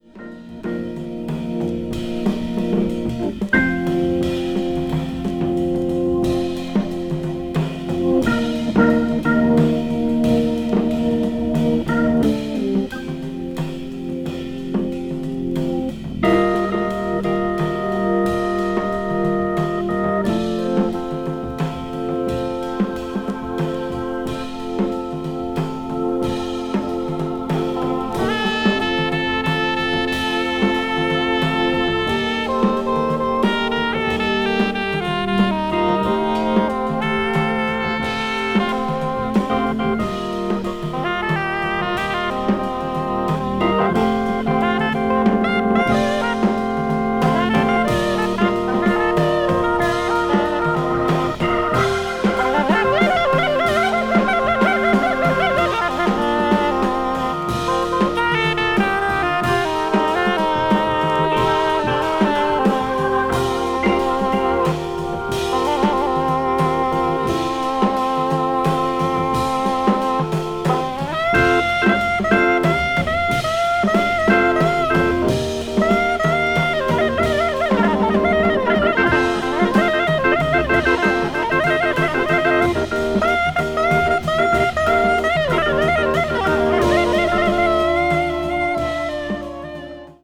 avant-jazz   contemporary jazz   free jazz   spiritual jazz